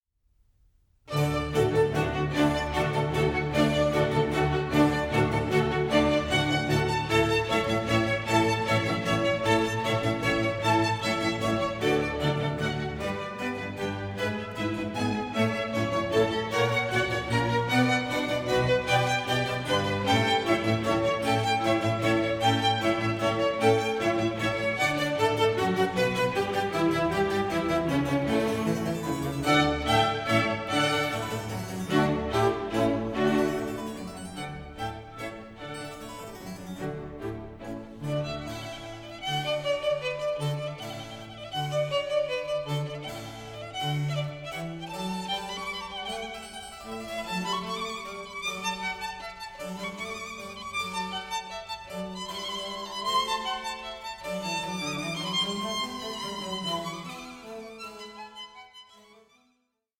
Concerto for Violin and Strings in D